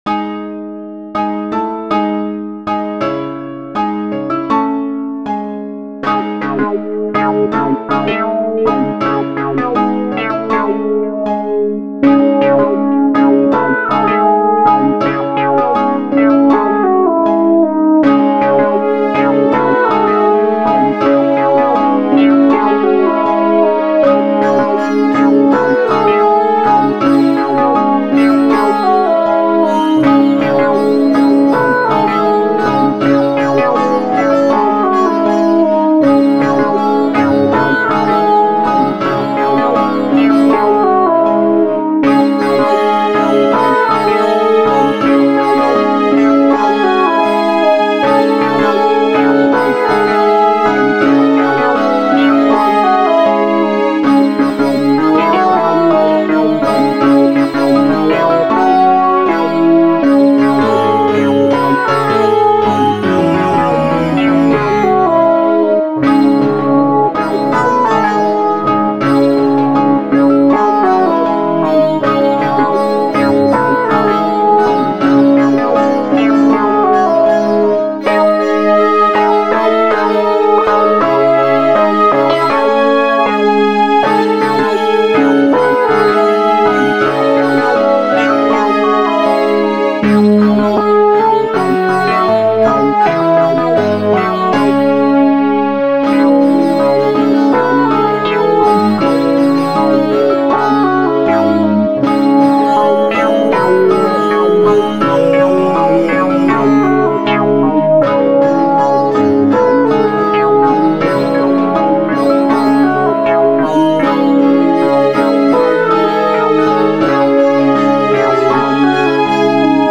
Posted in Classical, Dubstep